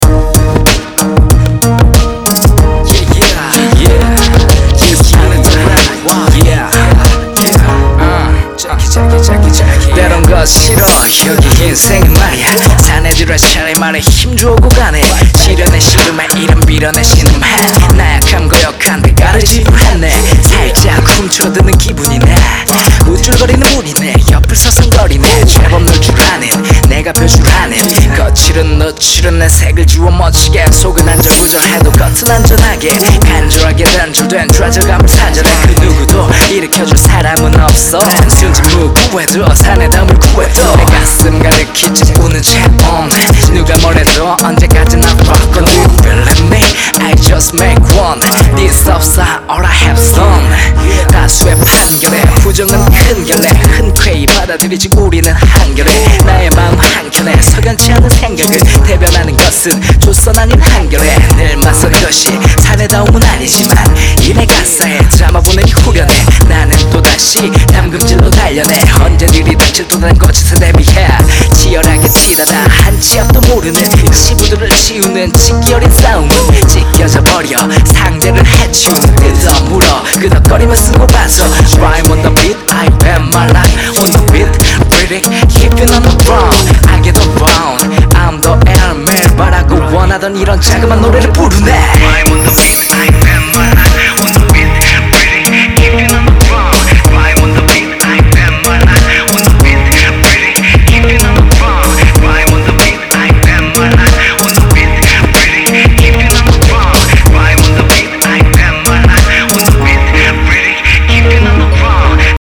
허접한 작법과 허접한 Rap이지만, 한번 올려 봅니다^^